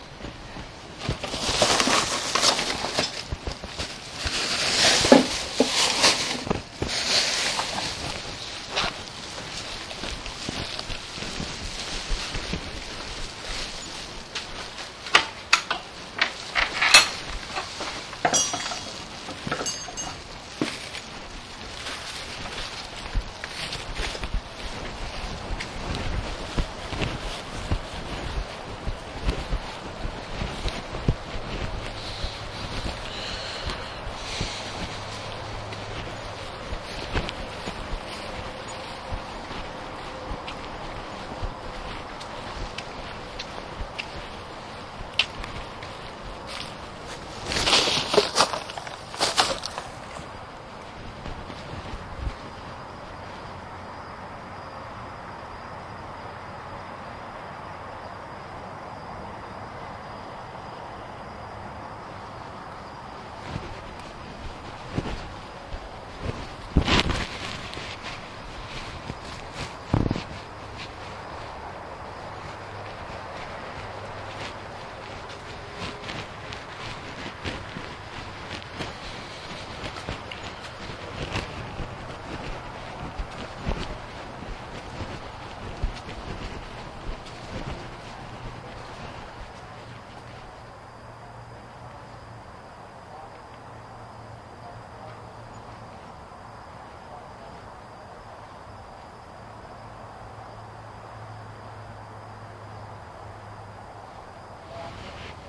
崩溃
描述：合成的，一个小玻璃物体碰撞的声音
Tag: 崩溃 玻璃 对象 syntesized